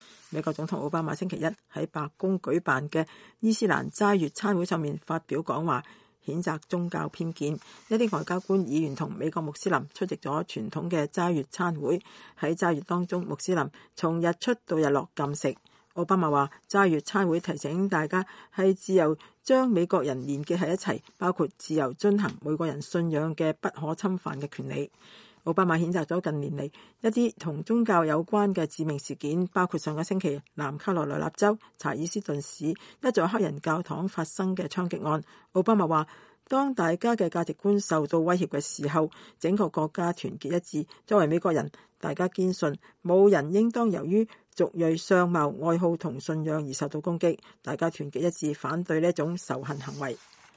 美國總統奧巴馬星期一﹐在白宮舉辦的伊斯蘭齋月餐會上發表講話，譴責宗教偏見。